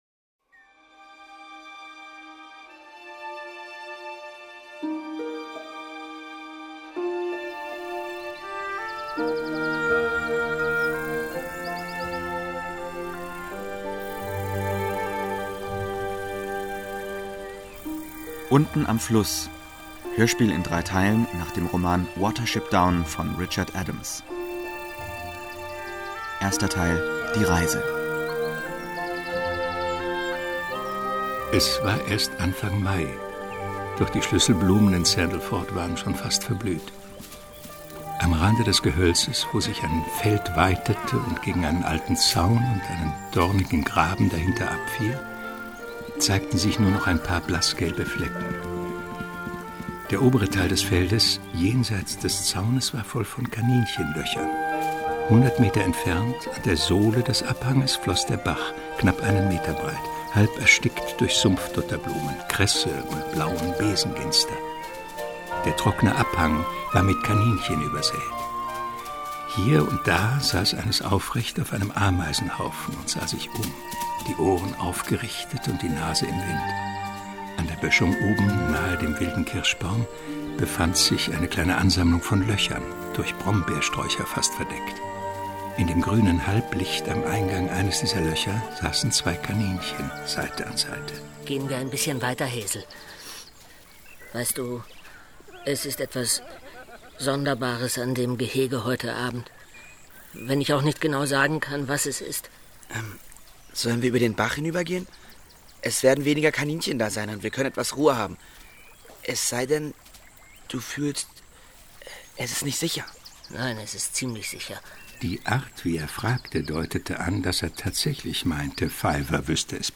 Unten am Fluss Hörspiel mit Peter Fricke, Jens Wawrczeck, Sophie Rois u.v.a.